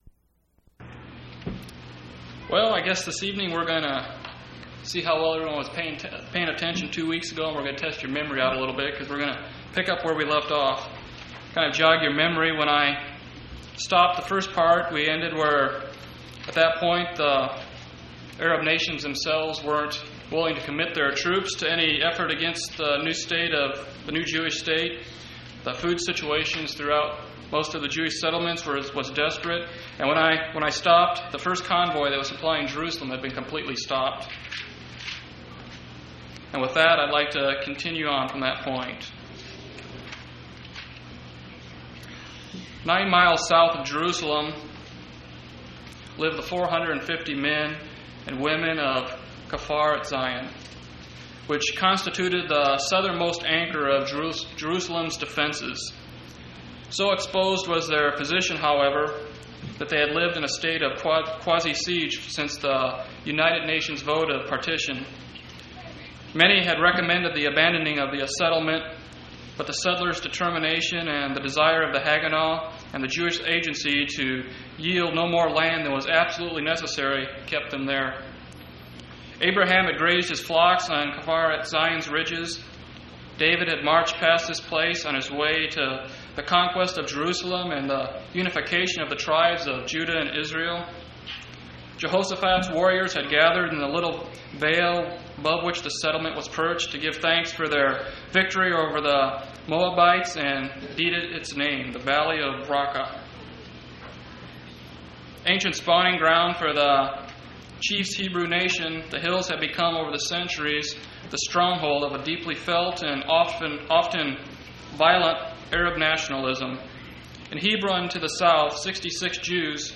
6/5/1991 Location: Phoenix Local Event